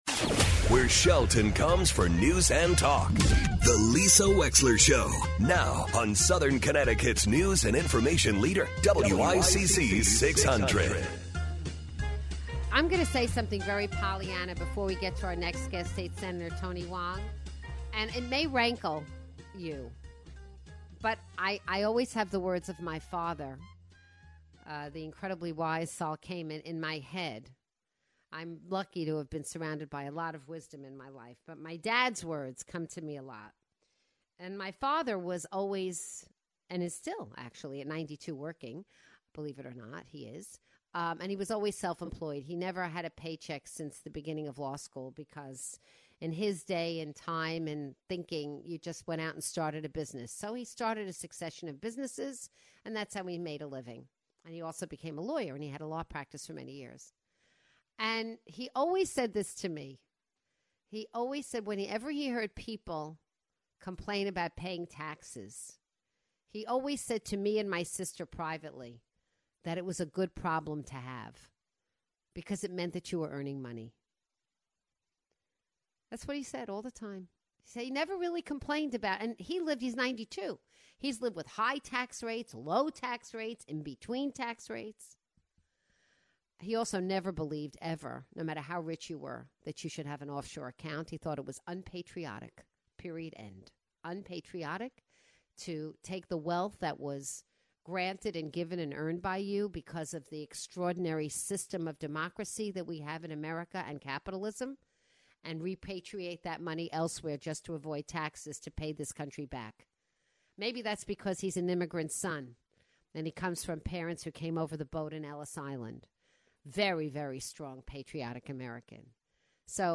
State Senator Tony Hwang calls in to discuss upcoming legislation and the state of insurance nationwide.